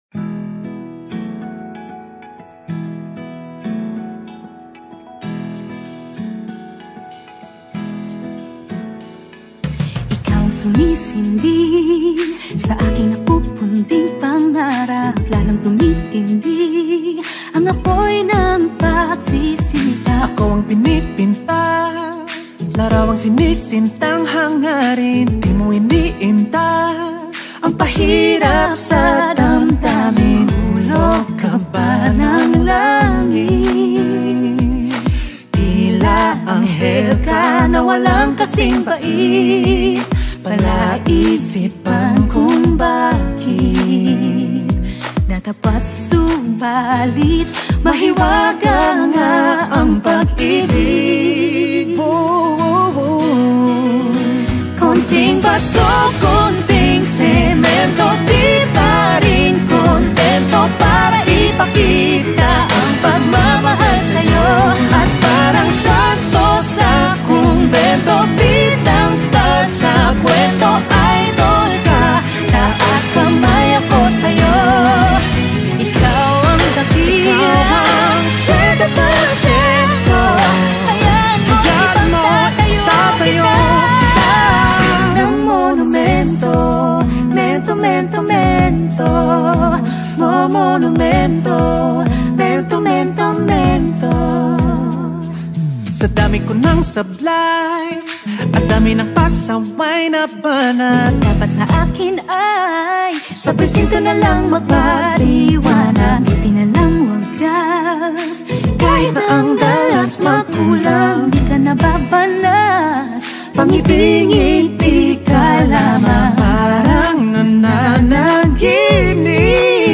Another OPM song passed my list and surprisingly